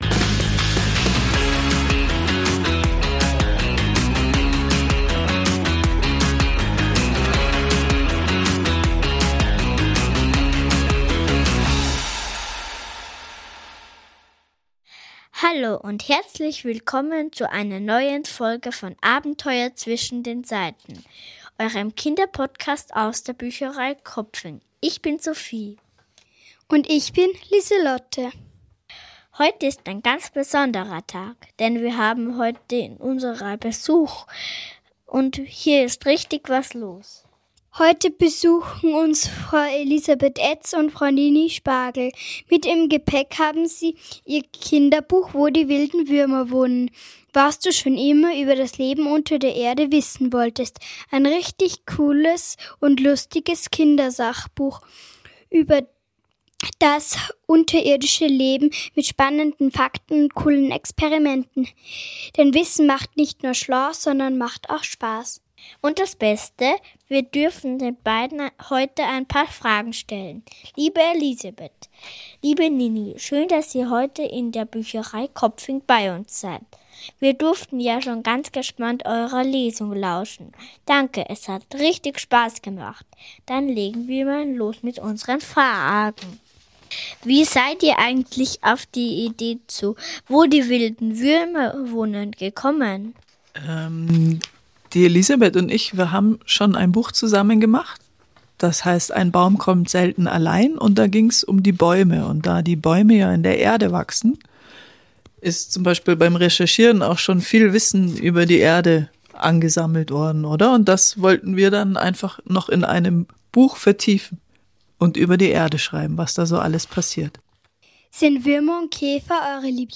Autorinnen im Gespräch über wilde Würmer, Wunder & das Schreiben für Kinder